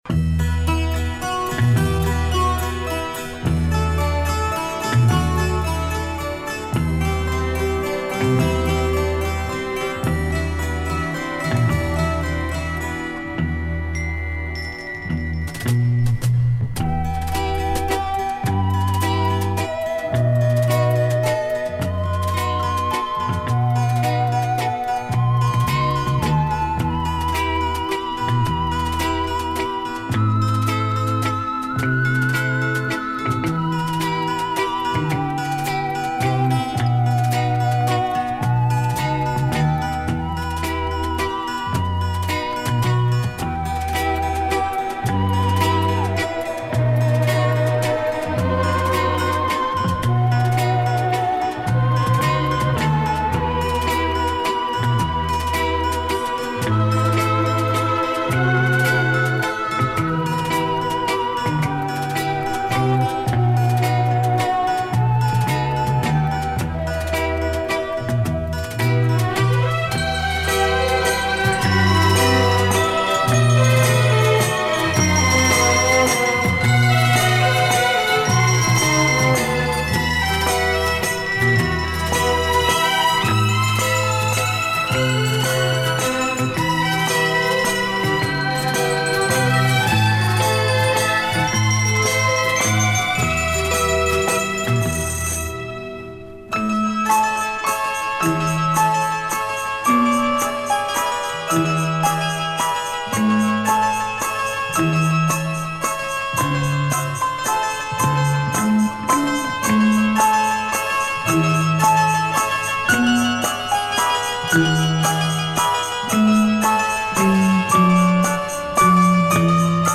Да, как джаз оркестр